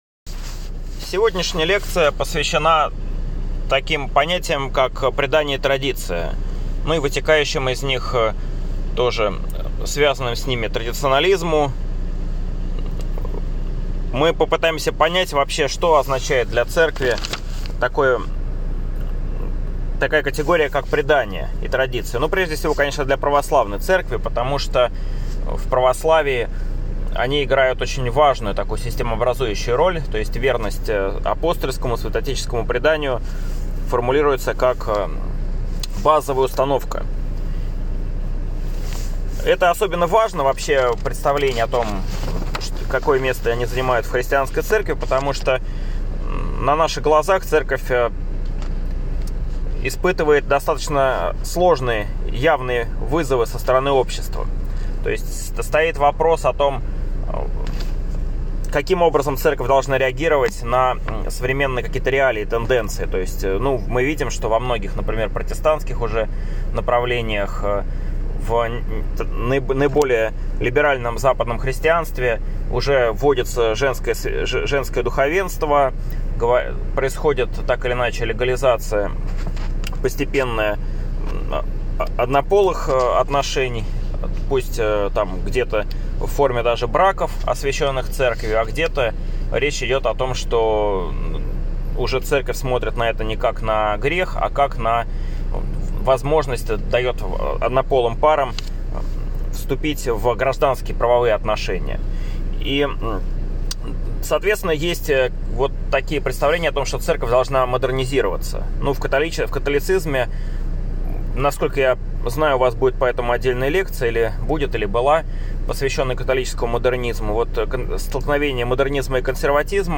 Речь пойдет о христианских ценностях и их девальвации в Новое и Новейшее время, о роли Церкви в политике, в формировании политических идеалов и в становлении правовой системы, о передаче традиций от одних христианских цивилизаций к другим, об особых религиозных культурах (монастырской, старообрядческой и др.) и об их вкладе в наследие цивилизаций. Курс будет прочитан шестью разными преподавателями, каждый из которых осветит важные и интересные для него стороны истории христианства.